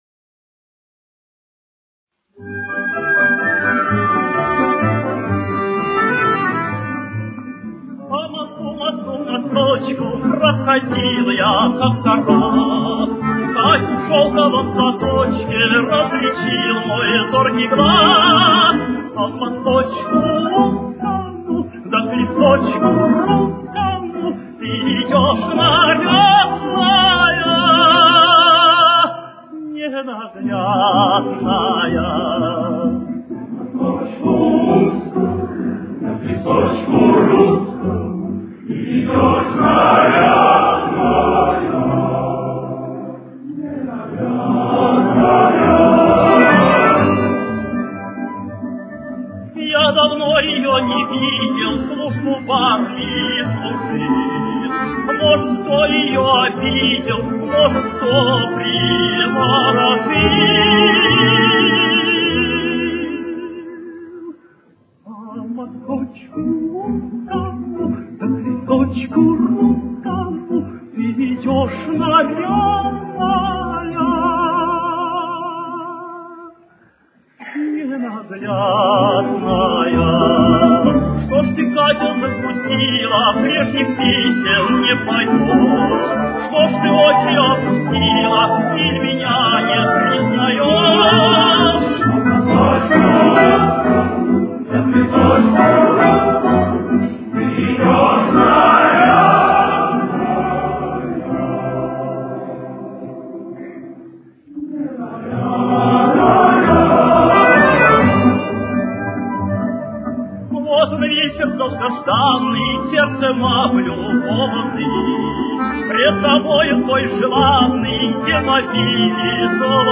Темп: 132.